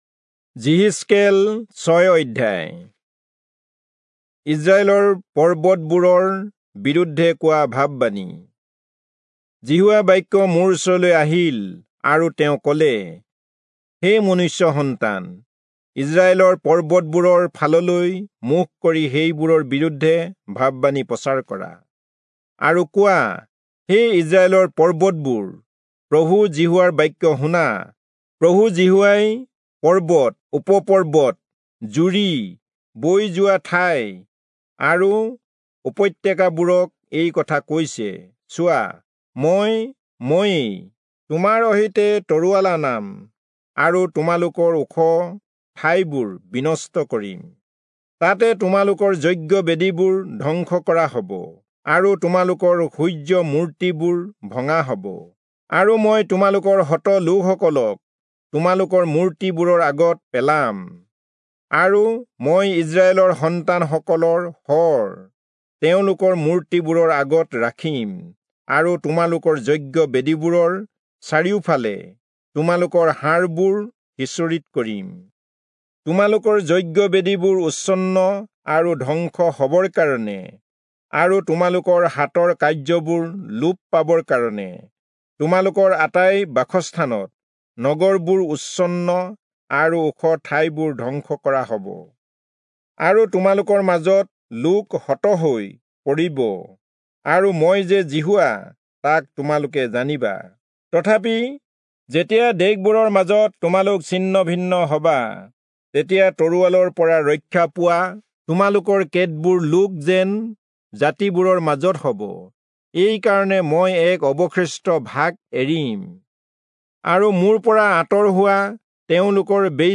Assamese Audio Bible - Ezekiel 12 in Erven bible version